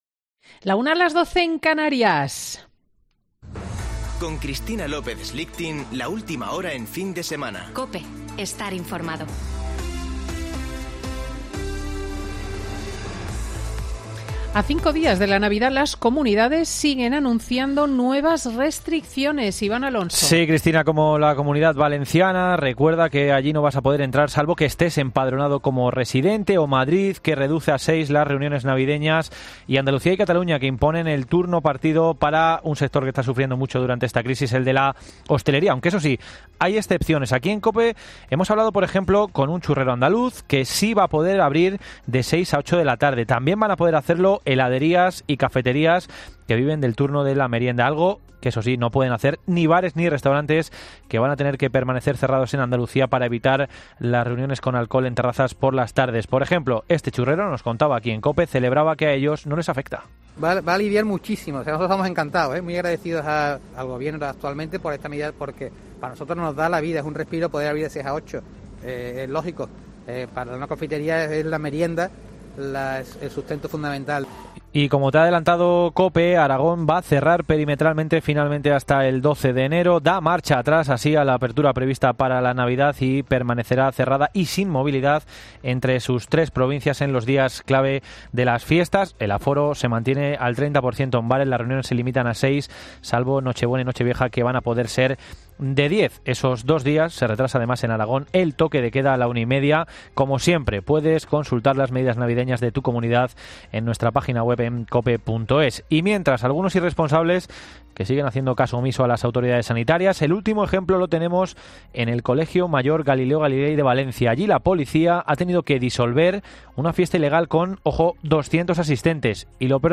Boletín de noticias COPE del 19 de diciembre de 2020 a las 13.00 horas